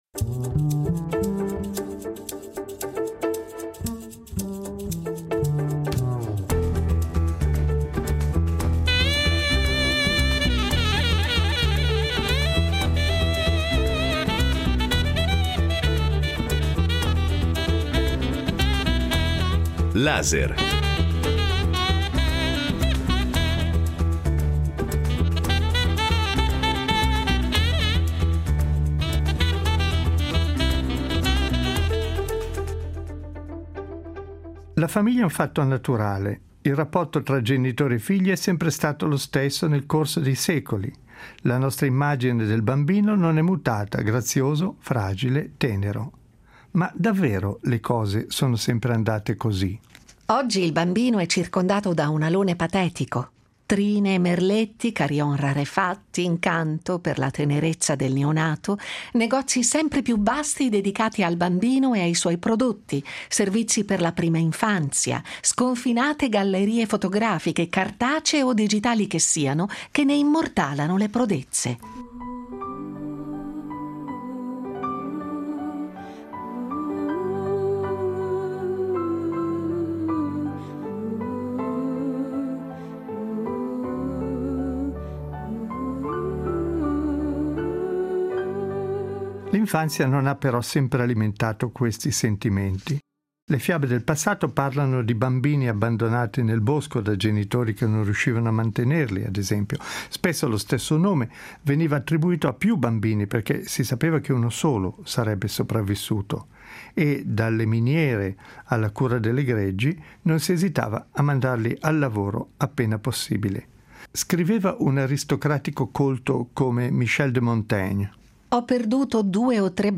Attraverso esempi, suoni, citazioni e testimonianze ricostruiremo questo affascinante cammino verso l’immagine del bambino come lo intendiamo noi, fino ai «genitori elicottero» e al «bambino di tiranno» di cui si parla nel dibattito pedagogico odierno.